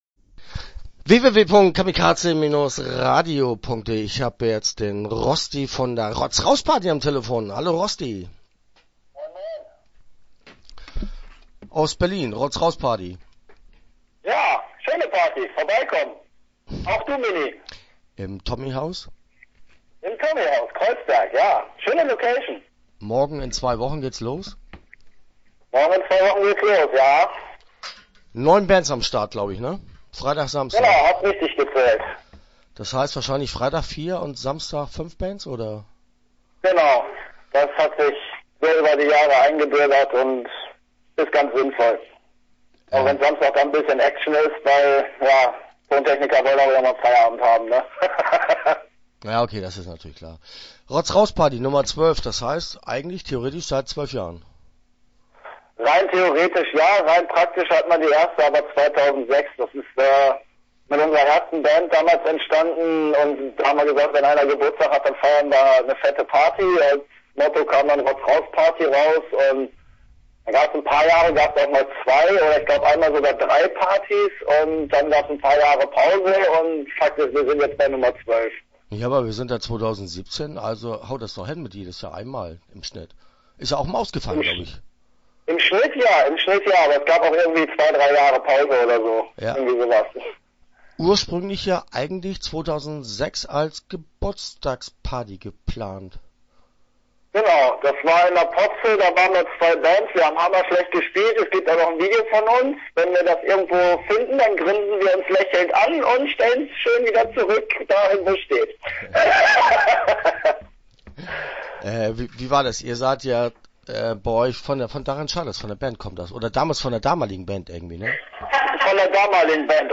Start » Interviews » Rotz Raus Party